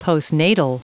Prononciation du mot postnatal en anglais (fichier audio)
Prononciation du mot : postnatal